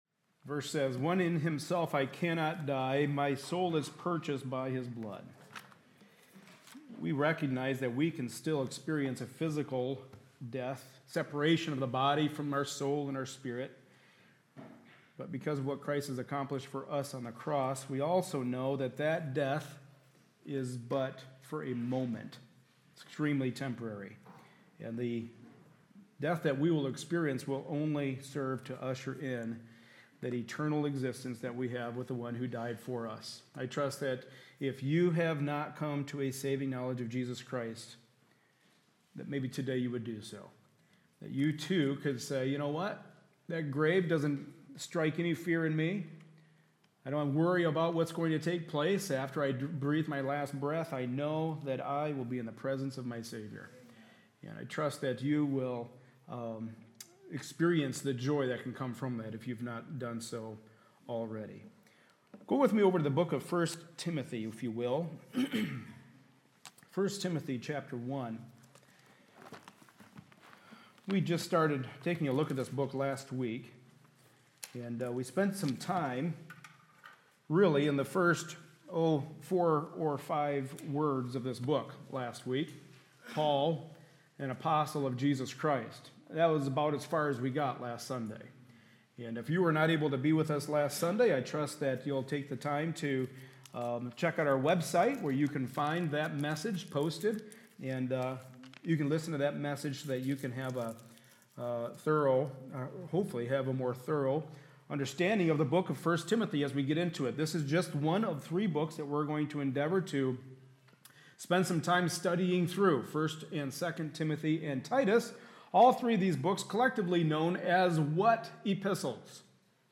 Service Type: Sunday Morning Service